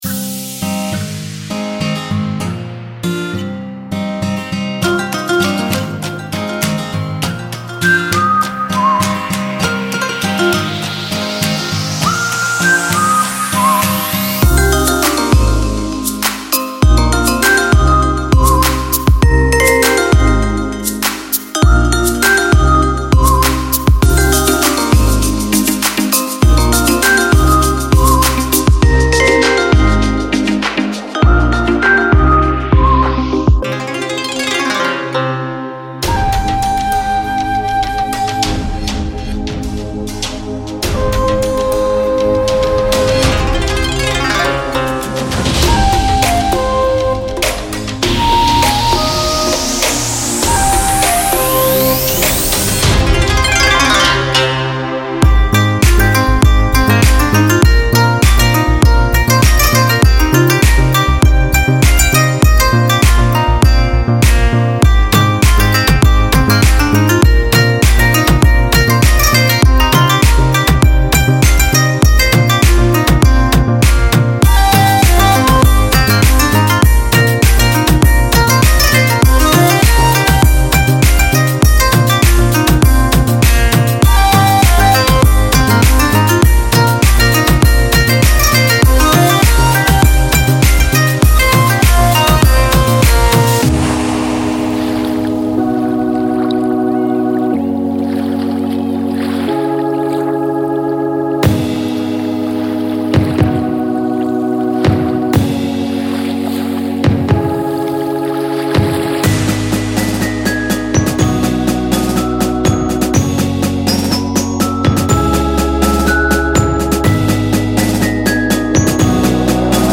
我们还包括28个原始录制的吉他环，供您集成到有机人体元素中。